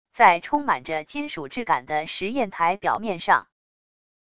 在充满着金属质感的实验台表面上wav音频